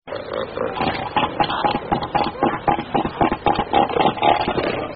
Som da Foca Monge -
Foca
Foca.mp3